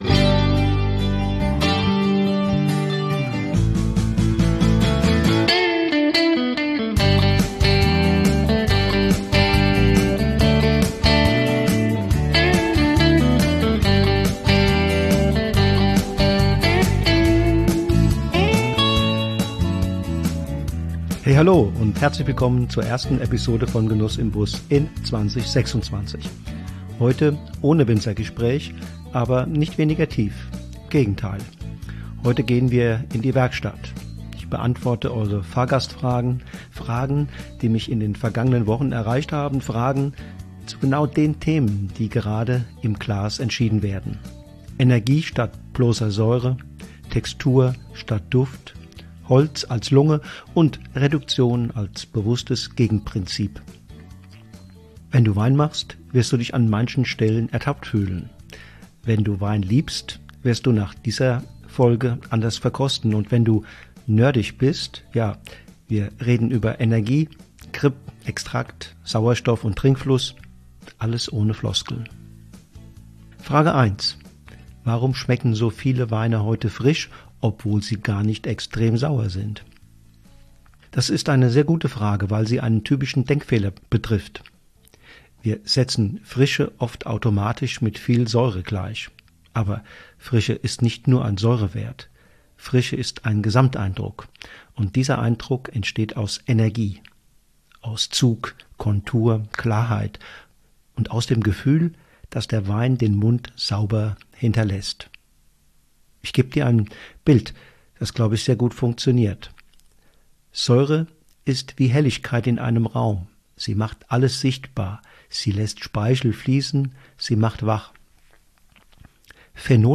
In diesen Solo-Folgen greife ich Fragen auf, die mir immer wieder begegnen, und beantworte sie so, dass du sie direkt im Glas nachvollziehen kannst – ohne Buzzwords, mit Sensorik.